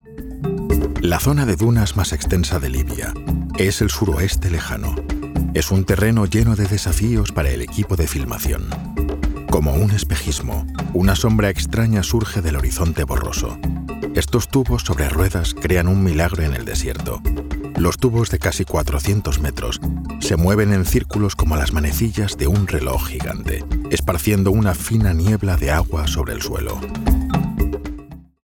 Dokumentarfilme
Mikrofon: Neumann TLM-103
Im mittleren Alter
Bass